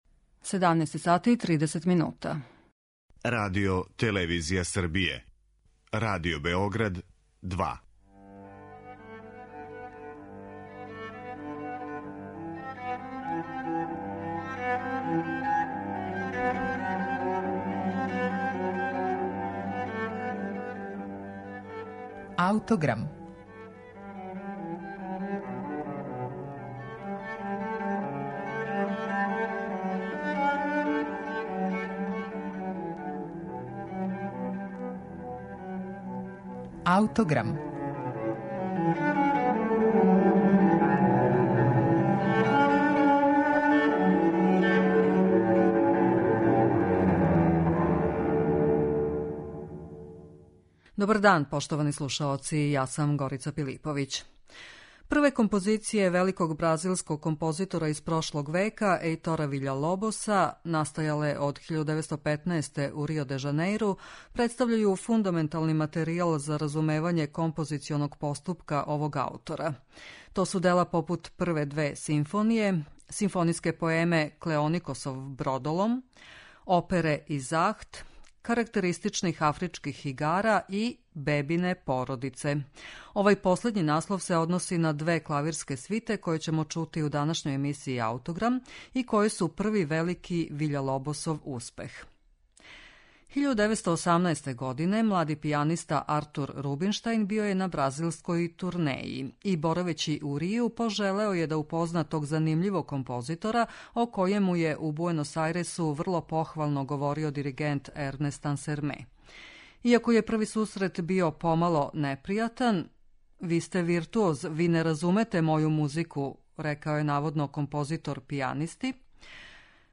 Клавирске свите Еитора Виља-Лобоса